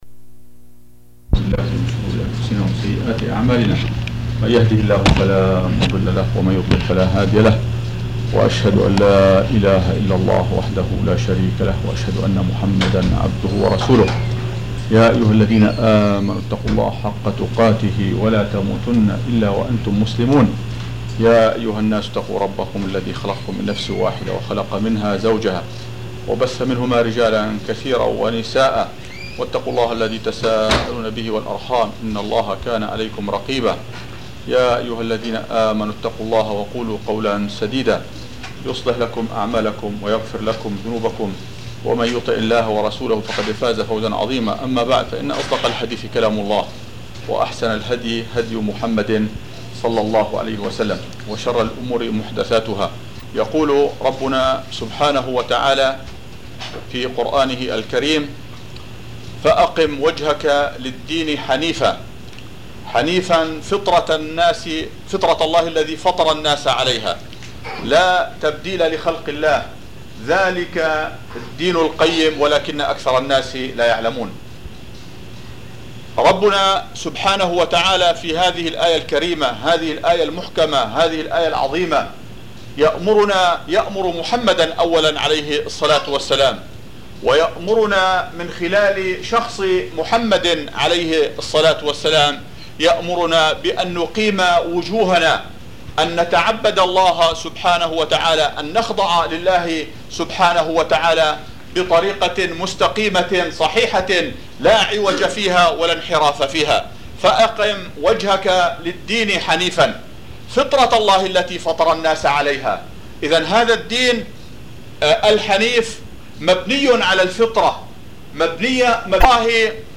محاضرة صوتية عن الفطرة